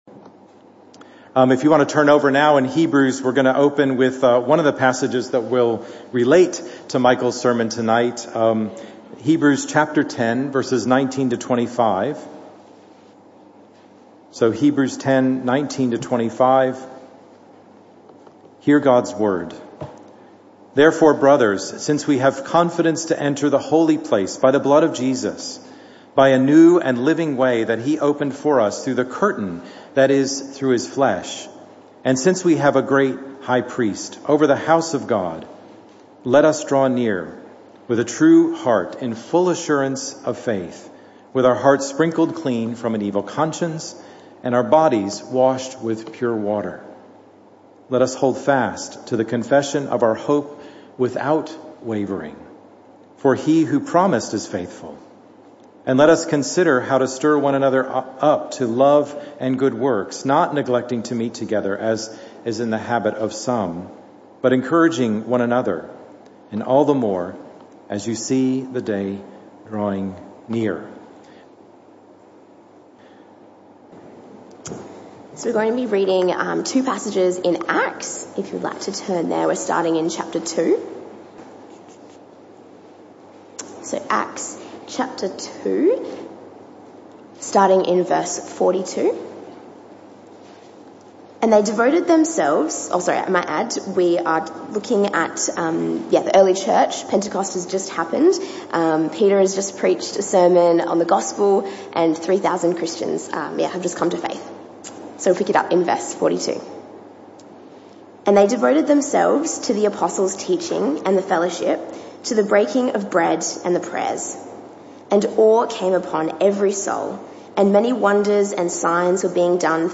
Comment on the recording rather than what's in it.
This talk was part of the AM/PM Service series entitled Loving Your Church.